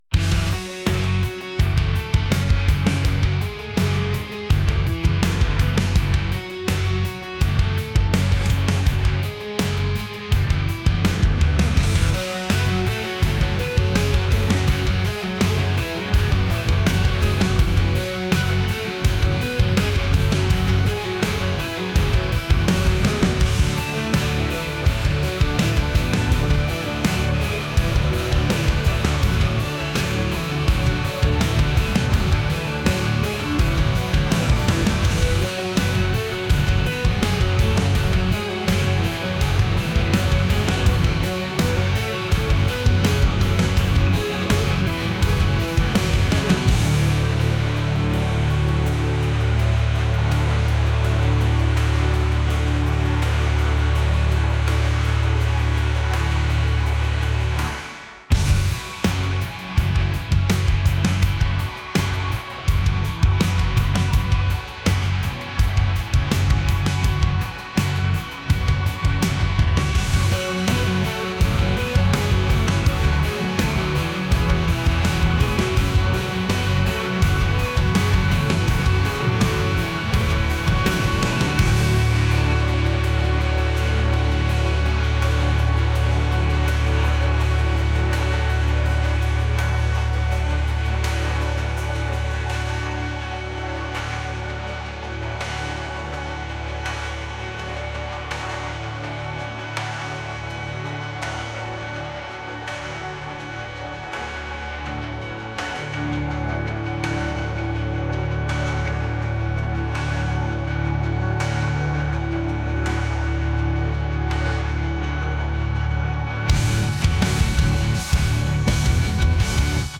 indie | rock | alternative